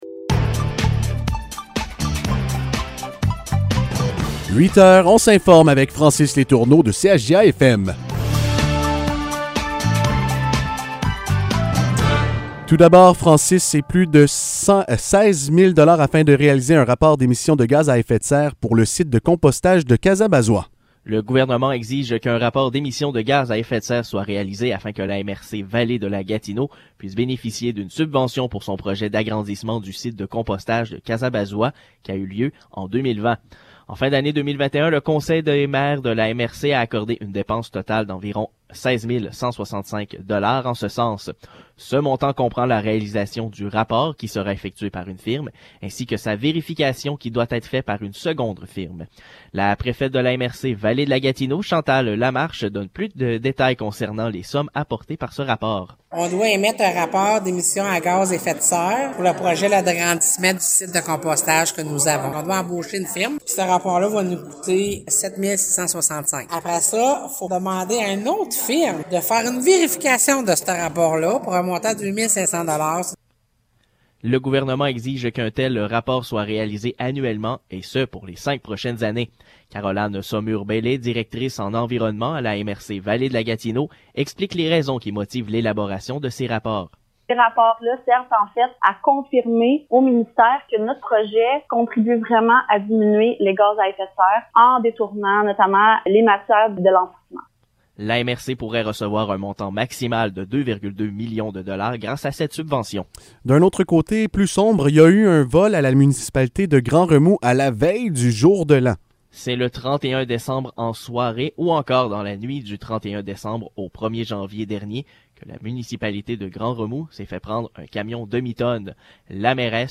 Nouvelles locales - 18 janvier 2022 - 8 h